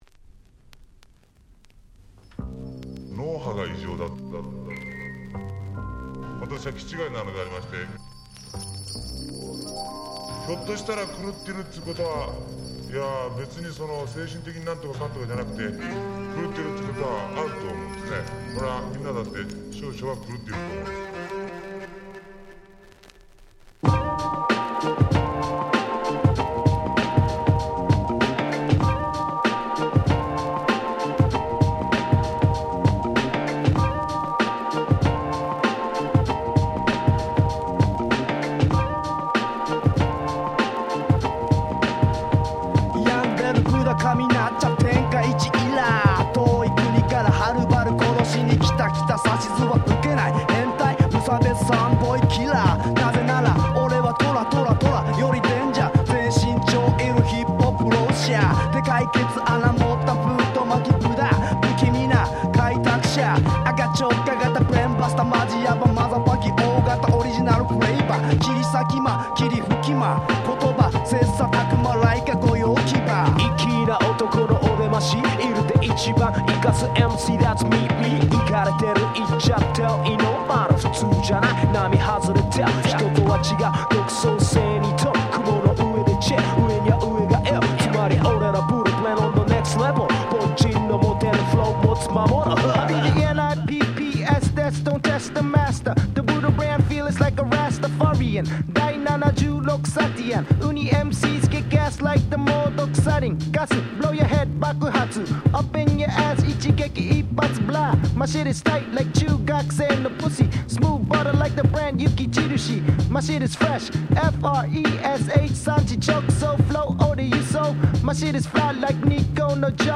95' Japanese Hip Hop Super Classics !!